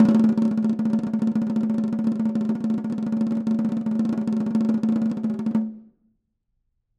Snare2-rollNS_v5_rr1_Sum.wav